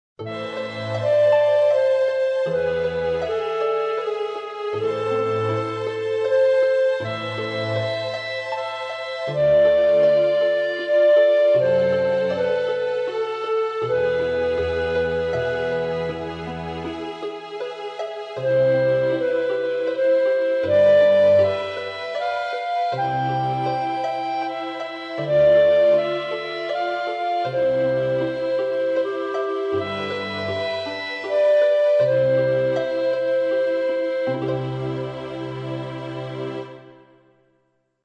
• il violino II procede omoritmicamente con il violino I, ma a distanza di una sesta inferiore;
• la viola procede omoritmicamente con il basso, ma a distanza di una quinta superiore, come indicato nel foglio di lavoro.